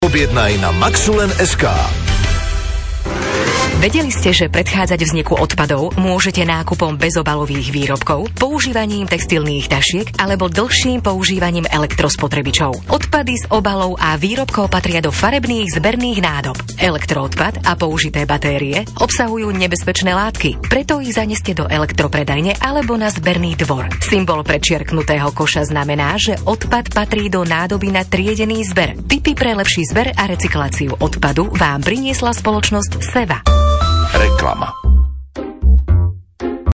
O správnych postupoch v oblasti triedenia odpadov vzdelávame obyvateľov, ako pôvodcov odpadov, aj prostredníctvom oznamov v regionálnom rozhlase Regina.